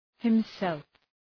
Προφορά
{hım’self}